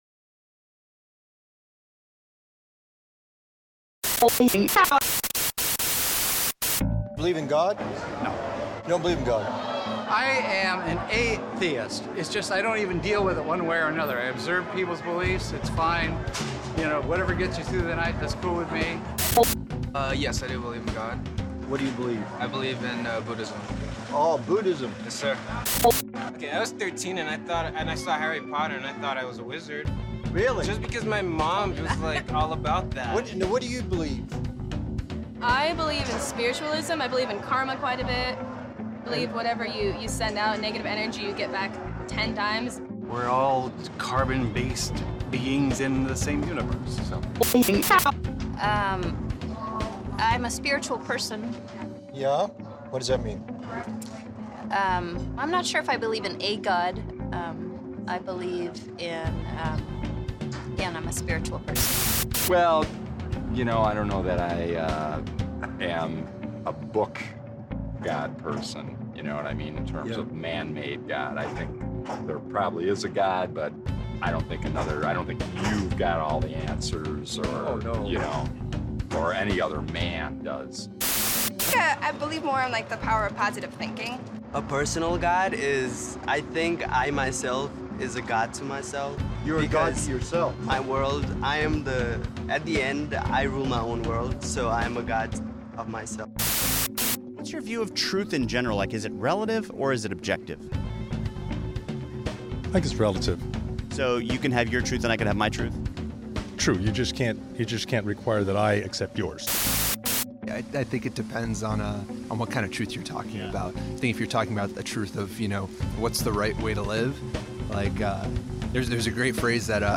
This We Know: A Sermon Series Through First John 1 John 1:1